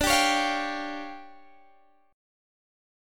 Listen to DmM7bb5 strummed